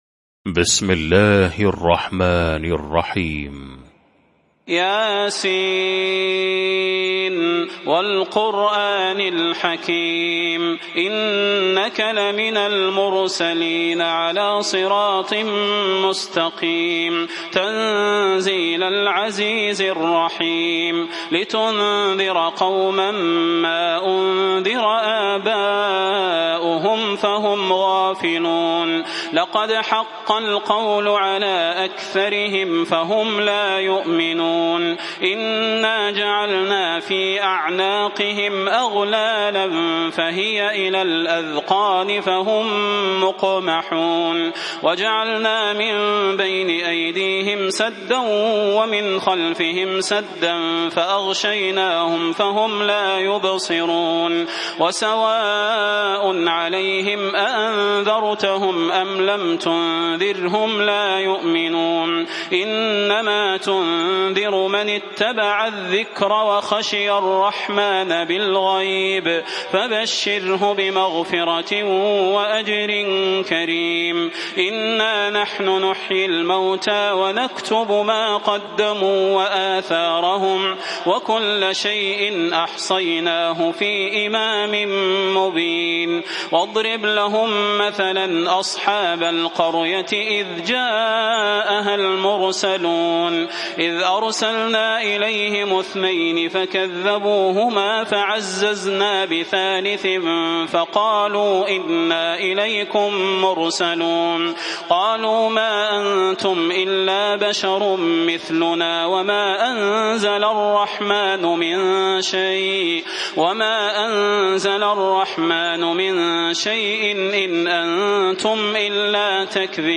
المكان: المسجد النبوي الشيخ: فضيلة الشيخ د. صلاح بن محمد البدير فضيلة الشيخ د. صلاح بن محمد البدير يس